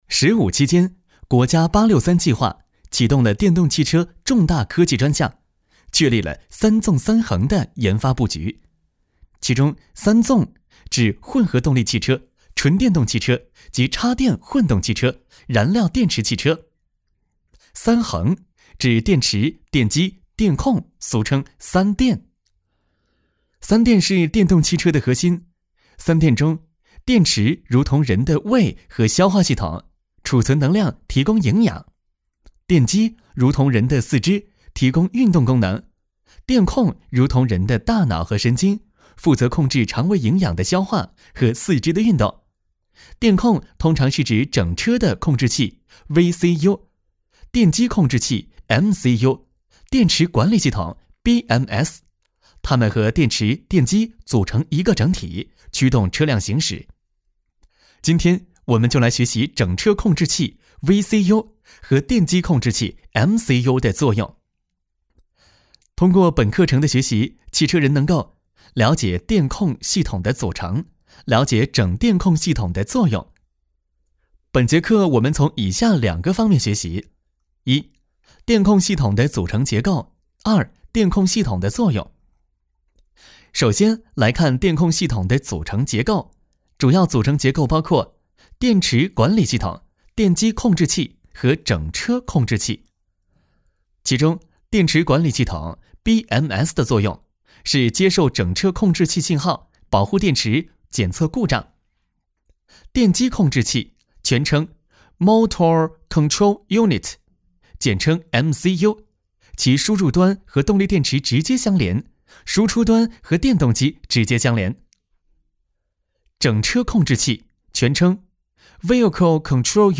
国语青年沉稳 、积极向上 、素人 、男课件PPT 、30元/分钟男9 国语 男声 课件 正式一点的课件 沉稳|积极向上|素人 - 样音试听_配音价格_找配音 - voice666配音网